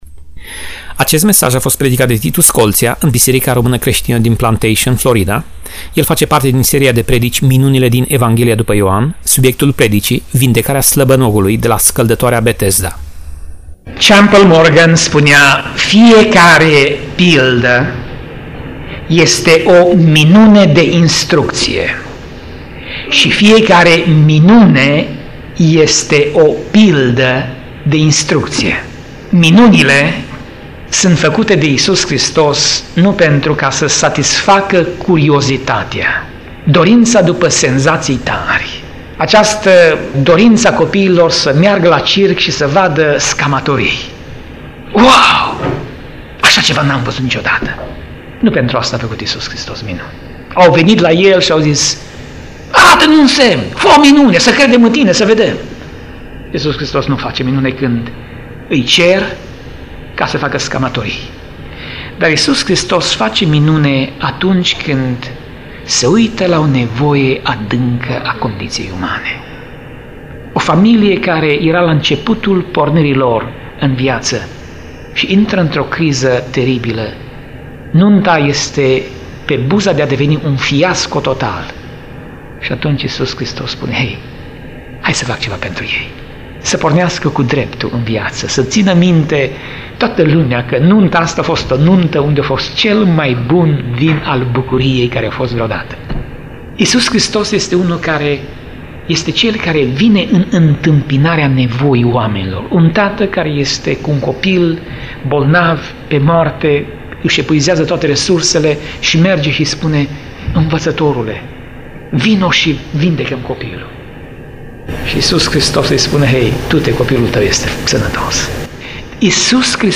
Tip Mesaj: Predica Serie: Minunile din Evanghelia dupa Ioan